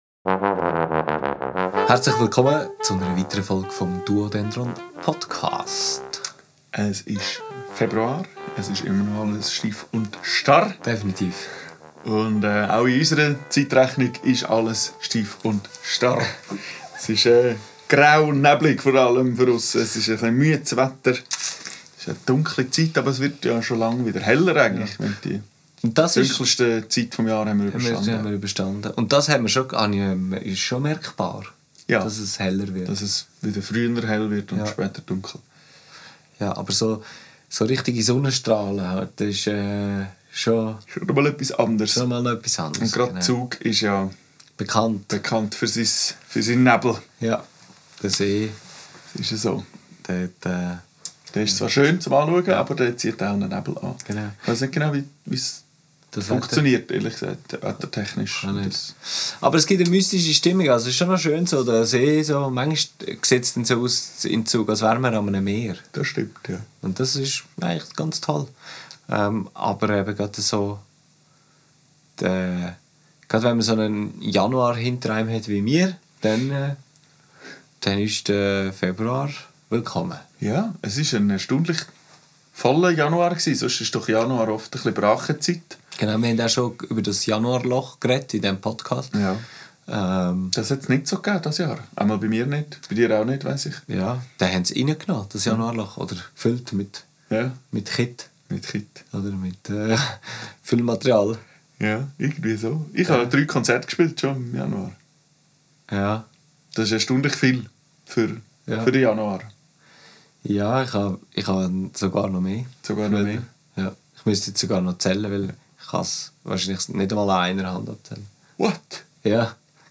Darum wird dann auch noch darüber improvisiert und wir erzählen ein wenig, was unsere Erfahrungen und Gedanken bezüglich Notation von Artikulationen sind.